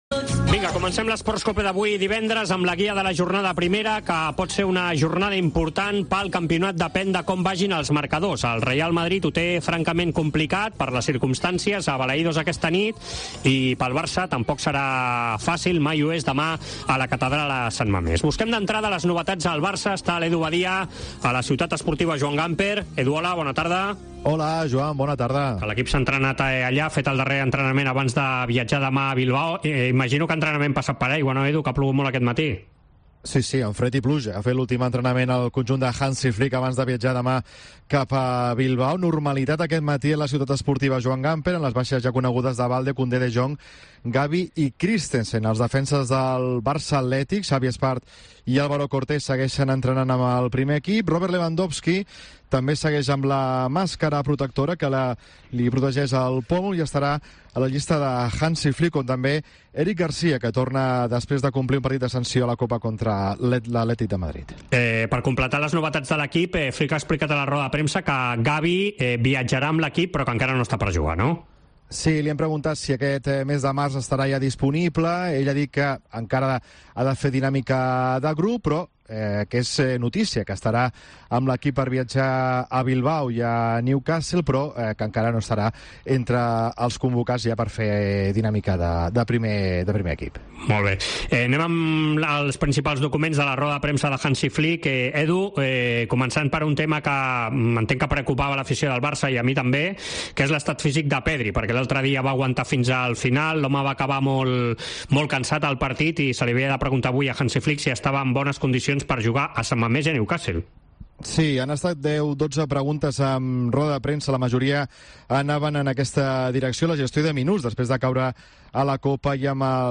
Rueda de prensa de Hansi Flick previa contra el Athletic Club de Bilbao